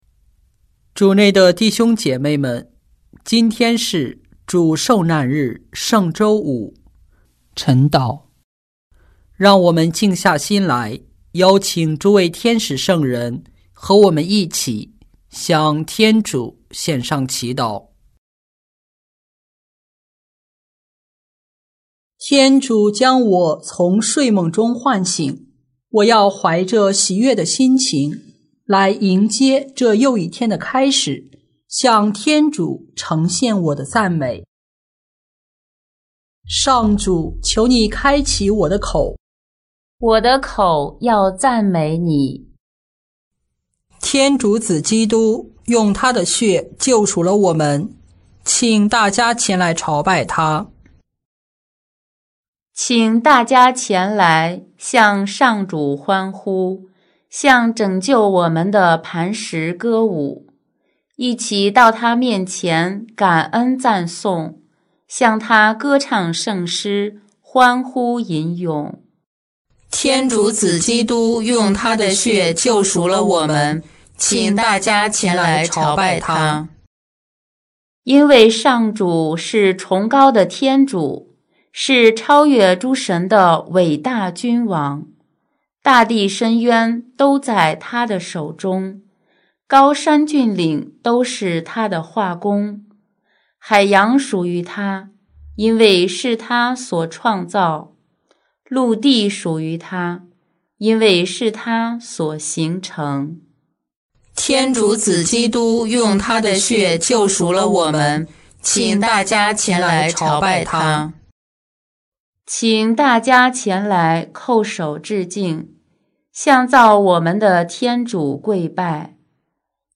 4月3日四旬期圣周五晨祷